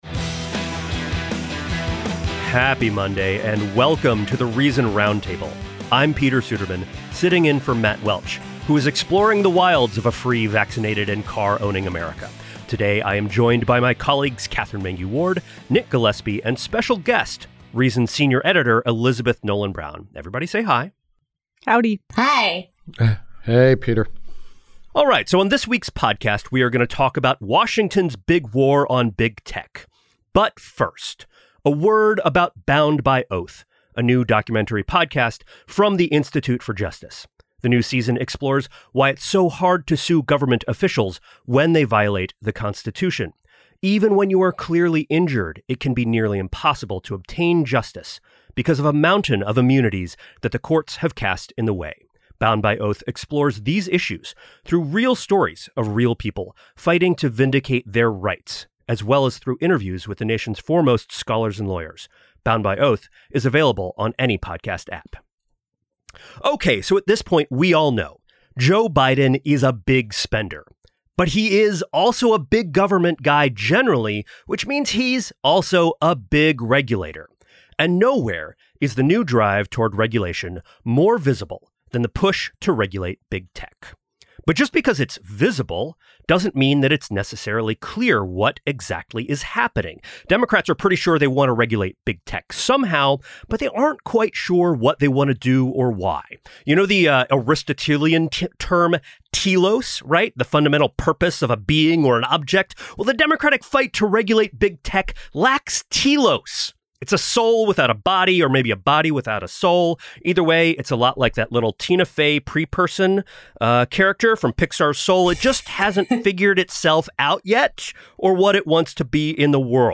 The Reason Roundtable breaks down big tech regulation tensions, Georgia's new voting law, and, of course, weed.